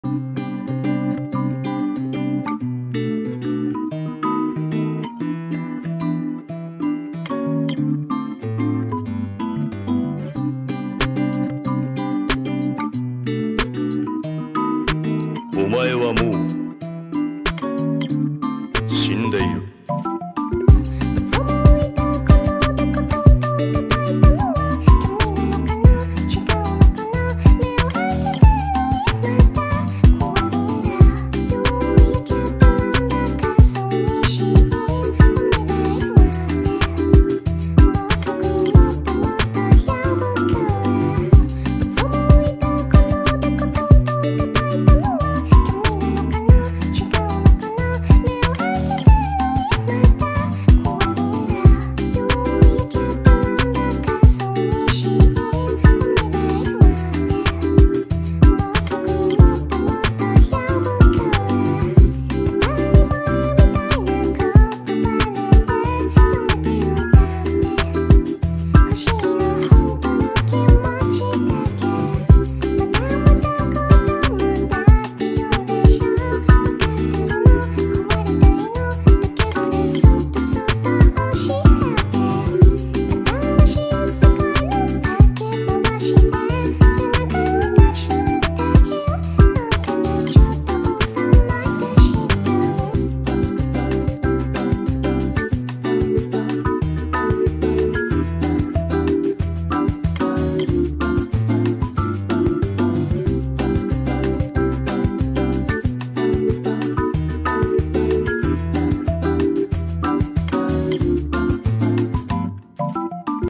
Type Beat